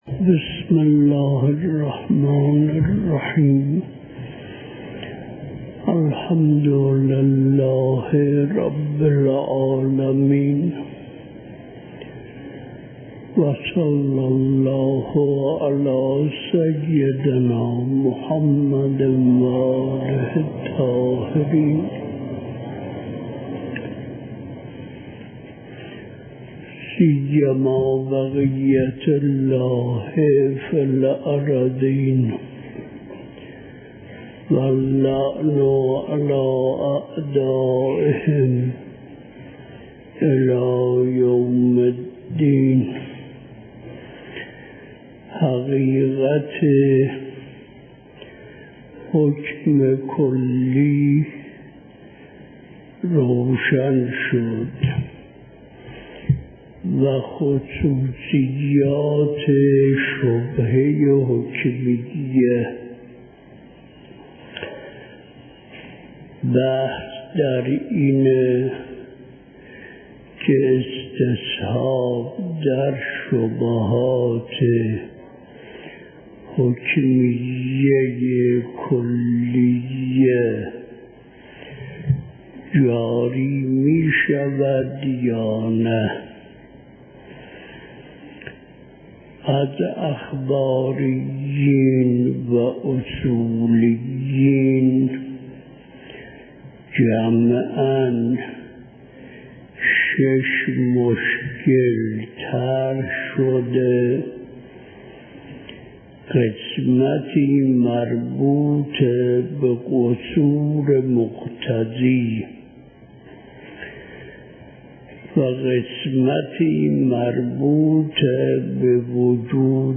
حجم: 10.2 MB | زمان: 43:26 | تاریخ: نیمه شعبان 1382ش - 1424هـ | مکان: مسجد اعظم